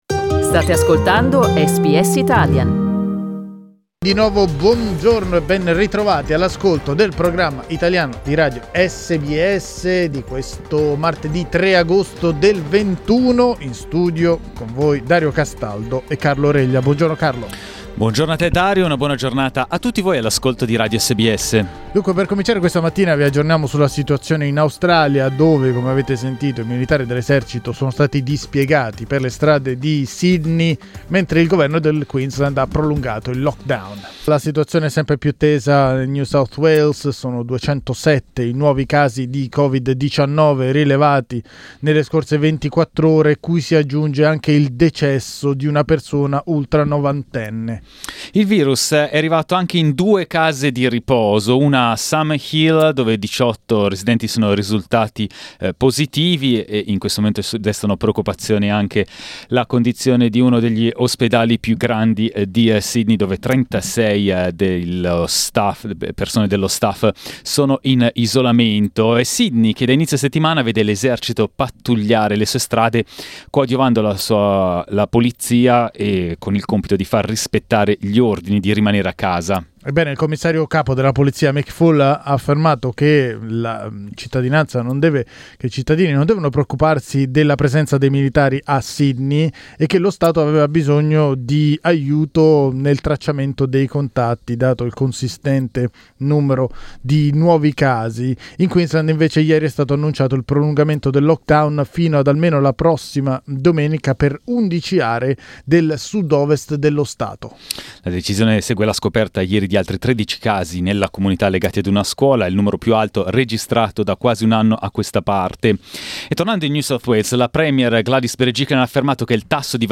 Ascolta il resoconto dall'Australia e dal Regno Unito di SBS Italian.